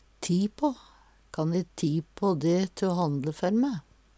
DIALEKTORD PÅ NORMERT NORSK ti på be om ein teneste, bruke av tida til ein annan Eksempel på bruk Kan e ti på de te o handle før me?